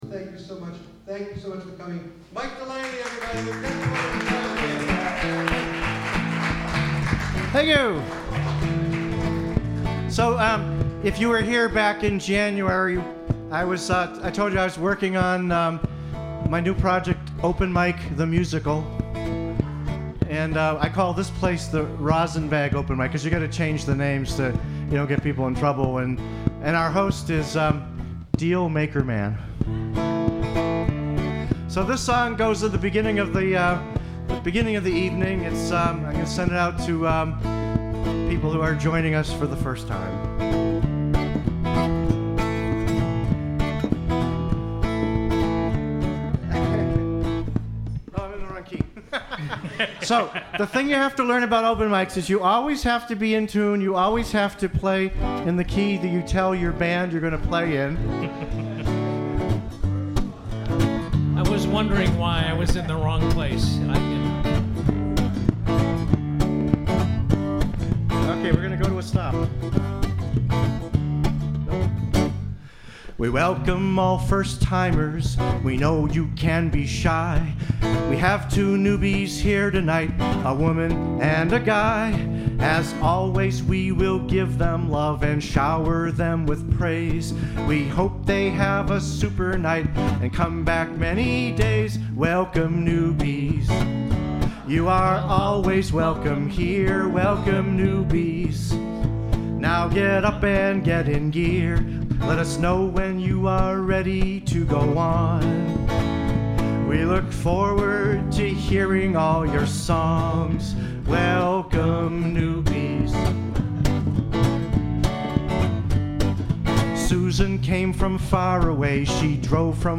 6_Welcome Newbies - at ROM.mp3